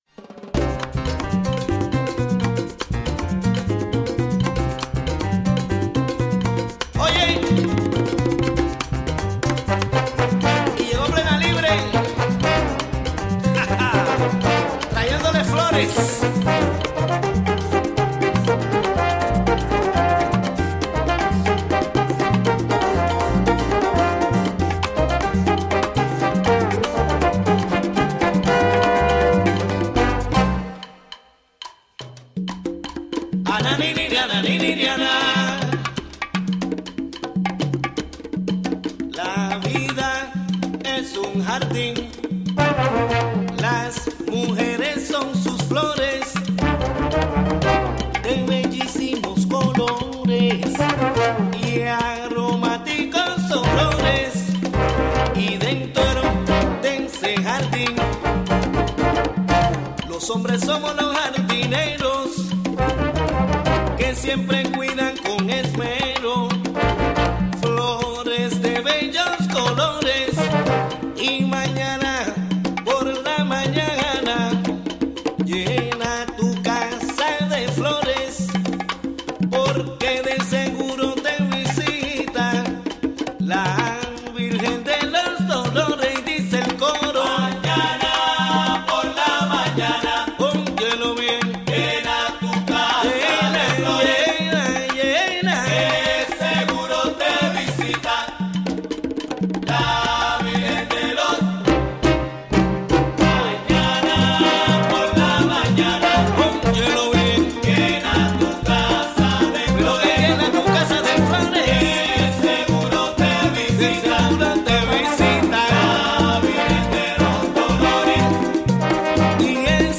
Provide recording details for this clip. WGXC Hudson Studio